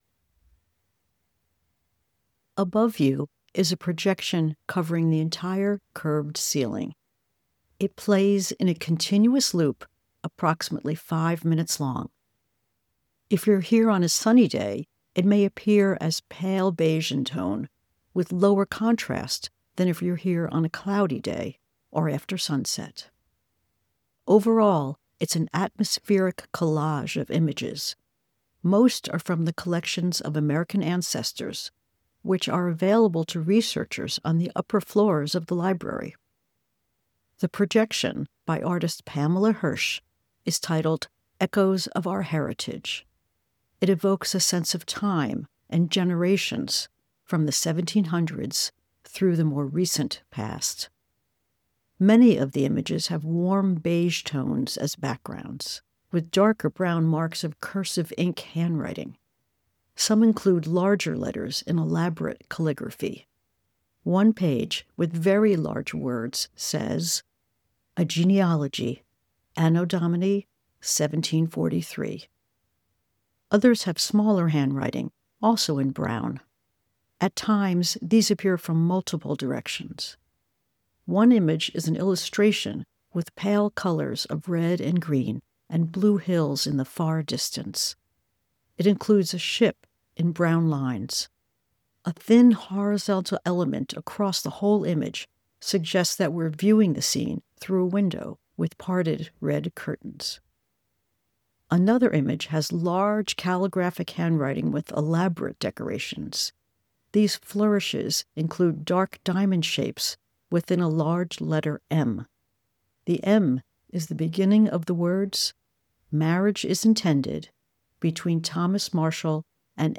Audio Description of 'Echoes of Our Heritage'
rotunda audio description.mp3